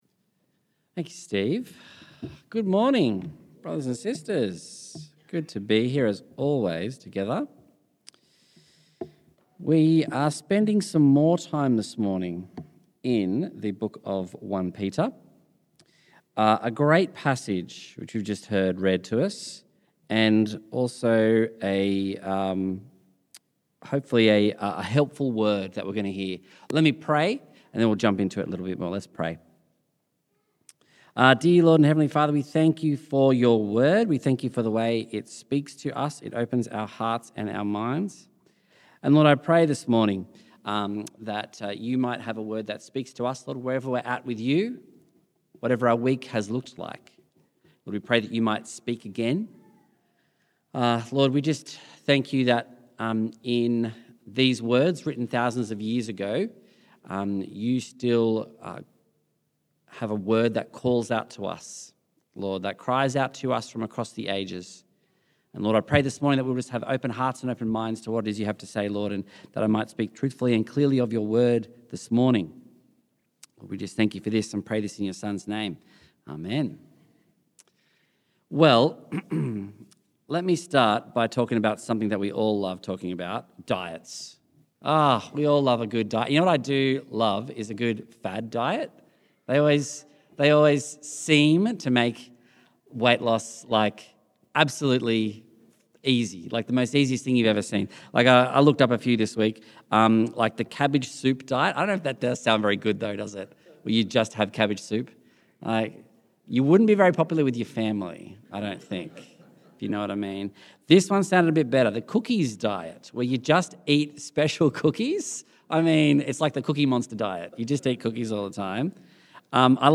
September Sermons